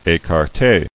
[é·car·té]